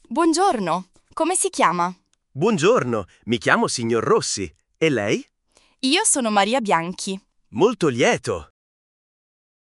🗣 Dialogo 2 – Incontro formale